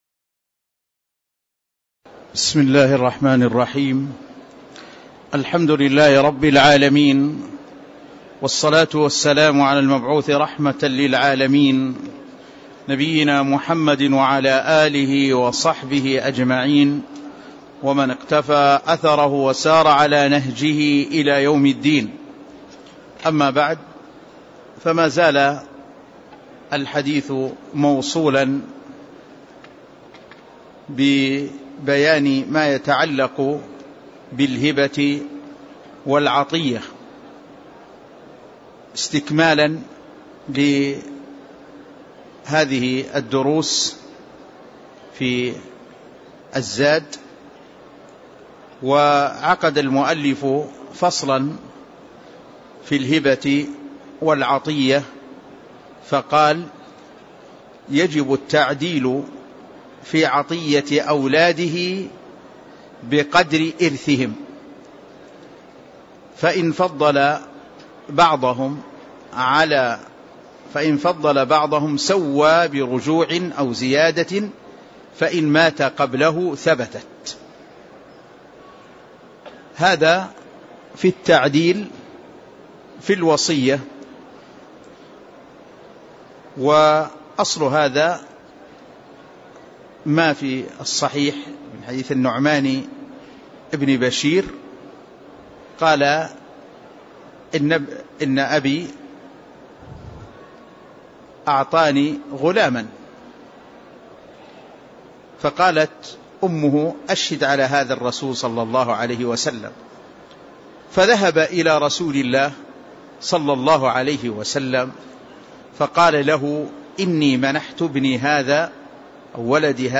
تاريخ النشر ٥ ربيع الأول ١٤٣٧ هـ المكان: المسجد النبوي الشيخ